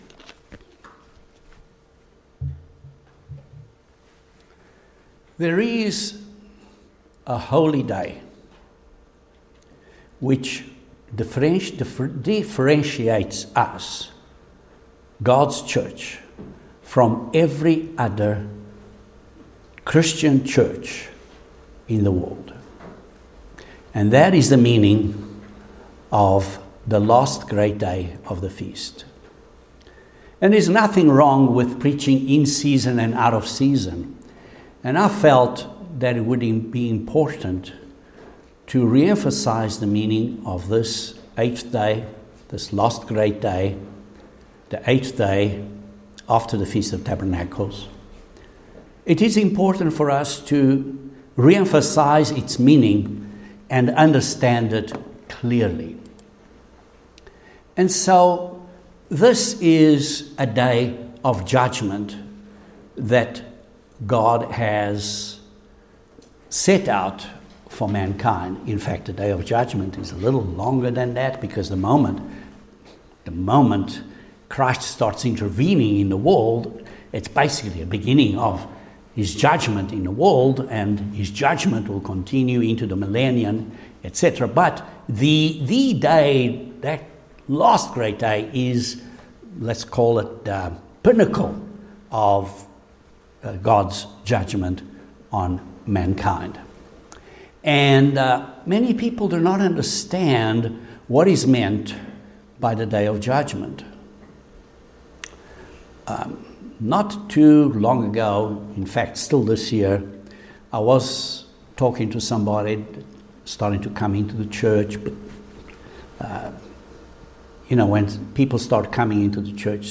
The meaning of the Last Great Day of the Feast, the Eighth Day is explained in this sermon.